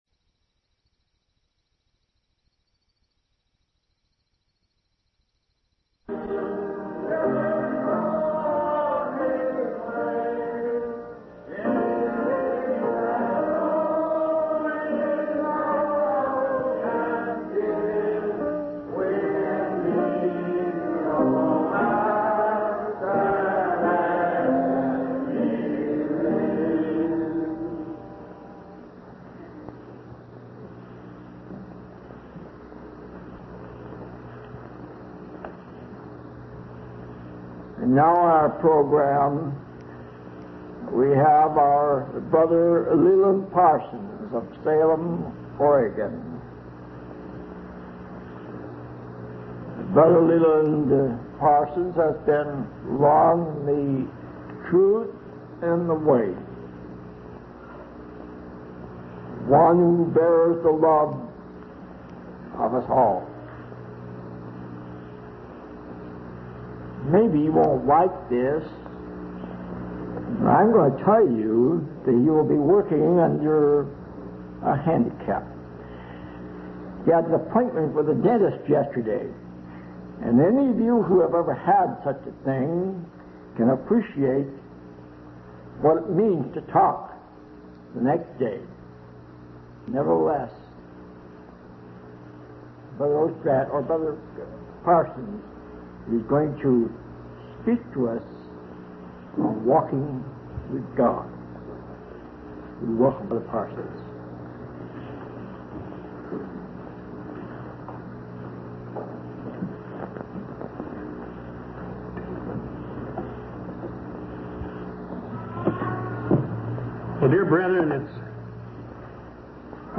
From Type: "Discourse"
Given at Asilomar Convention in 1955